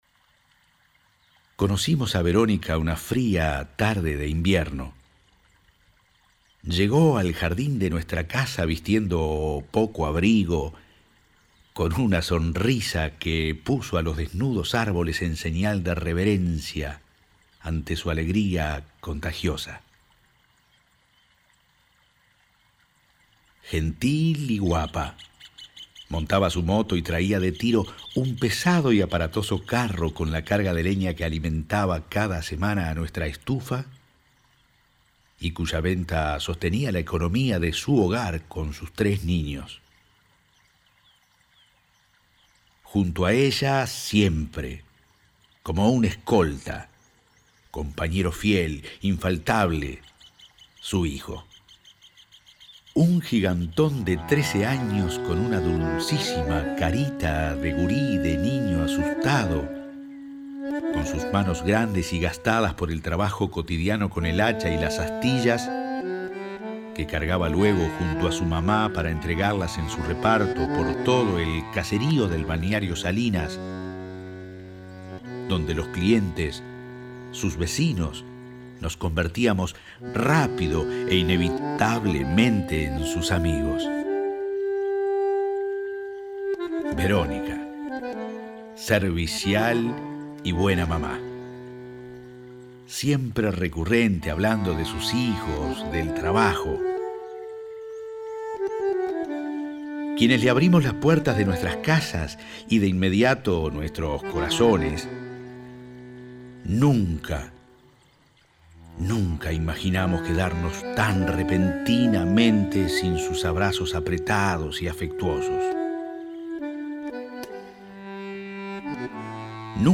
voz guía
Premezcla